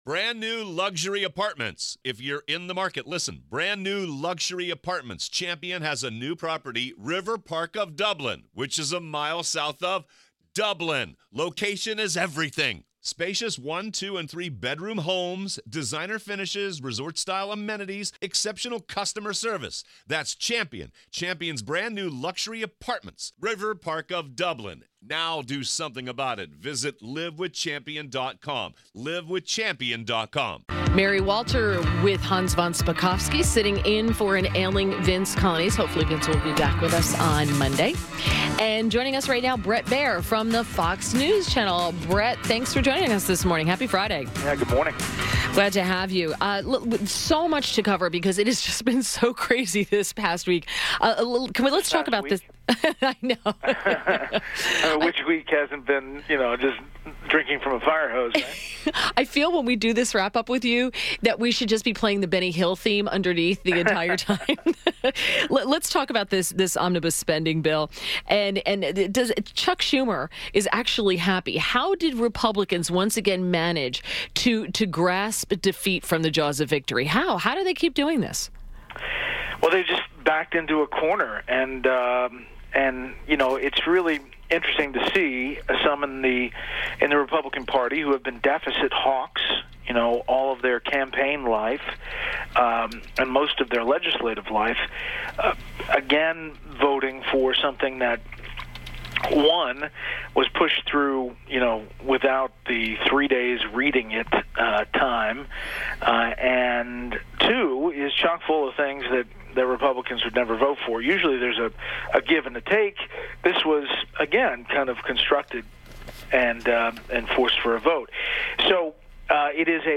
WMAL Interview - BRET BAIER - 03.23.18
INTERVIEW - BRET BAIER - Anchor, Special Report, Fox News Channel - discussed the Omnibus spending bill and Amb. John Bolton's hire for the White House.